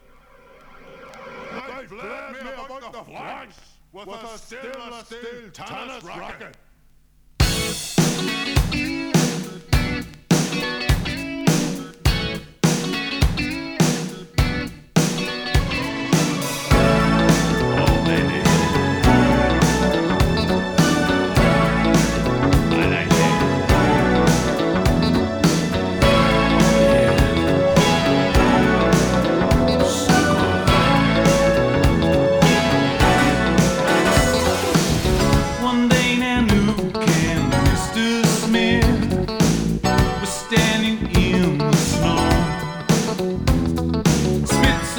前作での路線を突き抜け、フレッシュで良質なポップスが鳴らされた傑作。魅力的なメロディが溢れ、アレンジも凝ってます。”
Rock, Pop, Indie　UK　12inchレコード　33rpm　Stereo